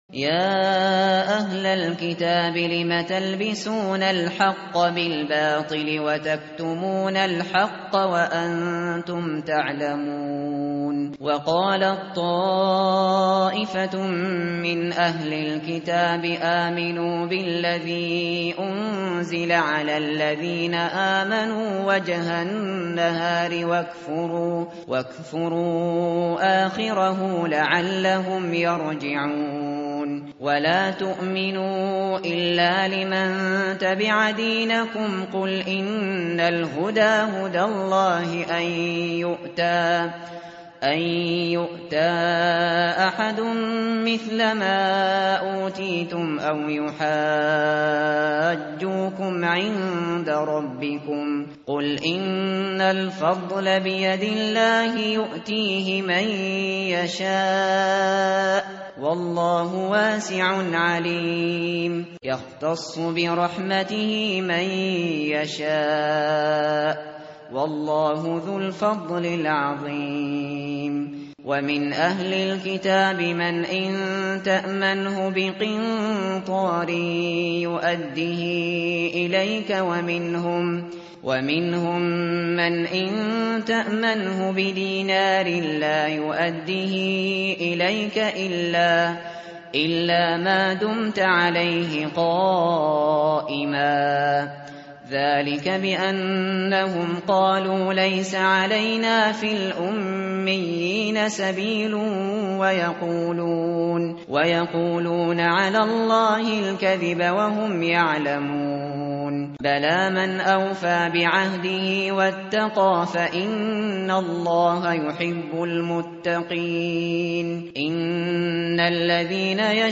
متن قرآن همراه باتلاوت قرآن و ترجمه
tartil_shateri_page_059.mp3